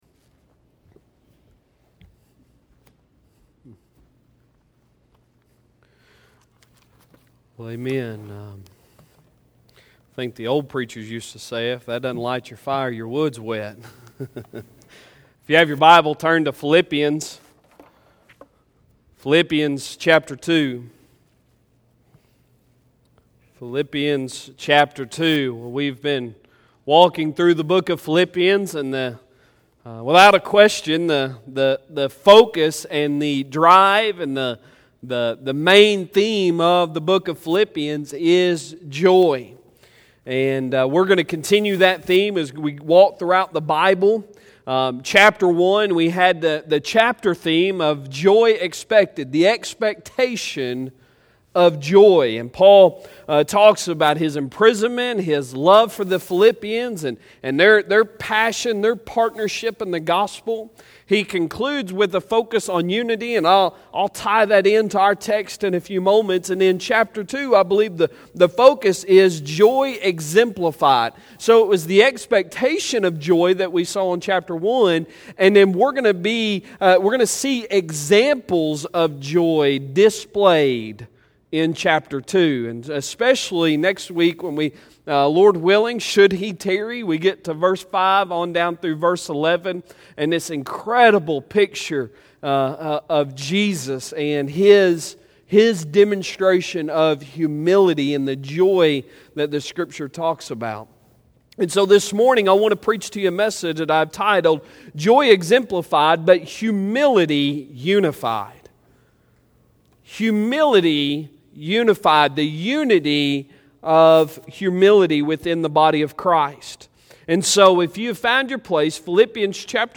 Sunday Sermon June 14, 2020